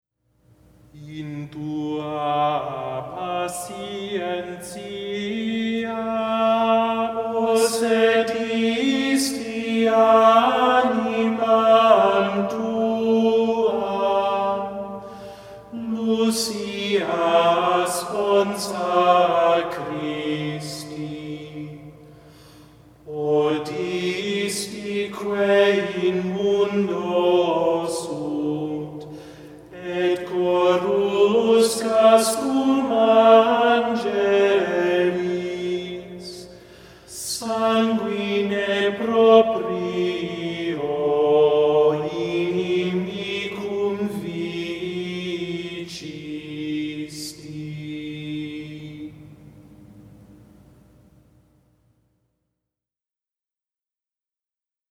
The recording here, however, is of the chant In tua patientia, the antiphon from the service of Vespers that is shown midstream at the top of the folio and was to be paired with a recitation of Mary’s canticle, the Magnificat. The chant concisely summarizes the figure of Lucy, concerned for the poor, given to Christ, and willing to be martyred.